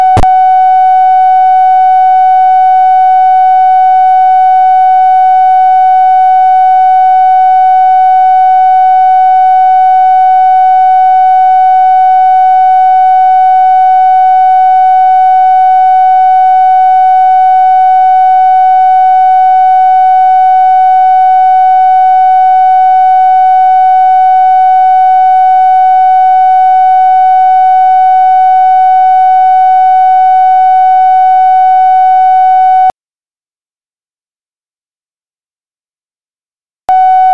Conversation with ROBERT MCNAMARA, April 22, 1964
Secret White House Tapes